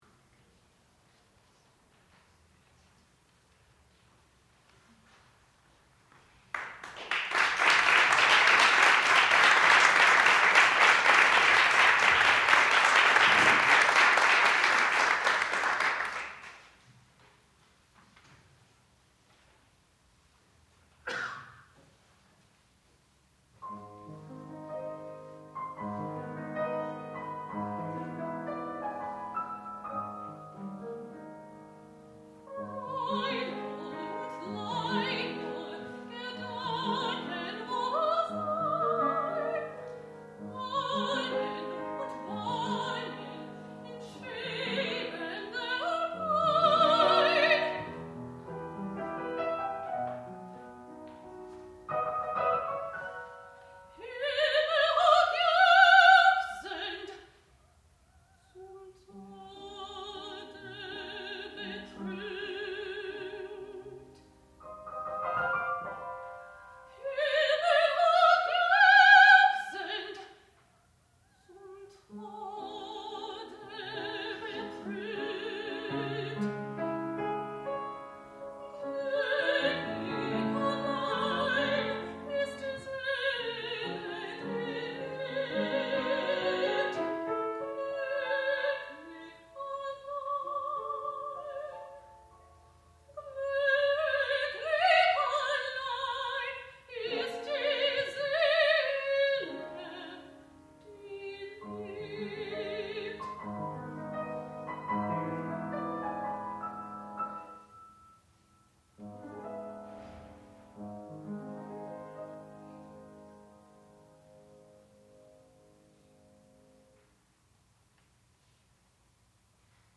sound recording-musical
classical music
piano
mezzo-soprano
Junior Recital